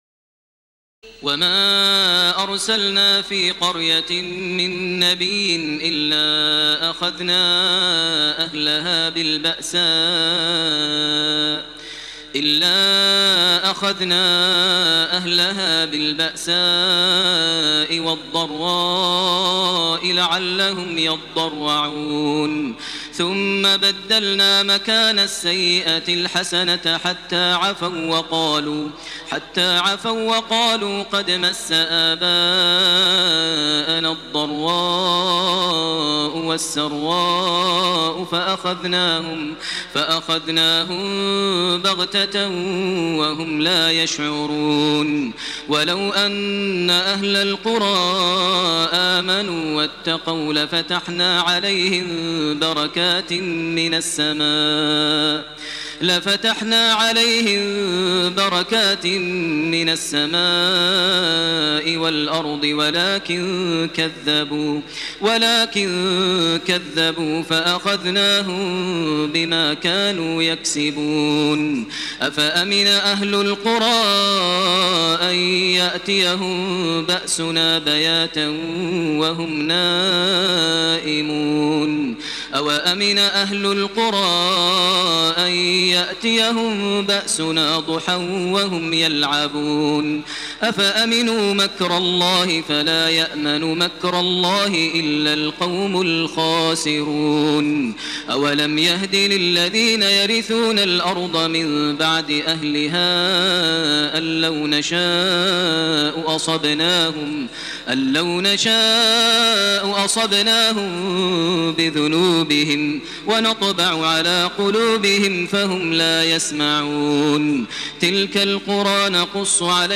تراويح الليلة التاسعة رمضان 1428هـ من سورة الأعراف (94-171) Taraweeh 9 st night Ramadan 1428H from Surah Al-A’raf > تراويح الحرم المكي عام 1428 🕋 > التراويح - تلاوات الحرمين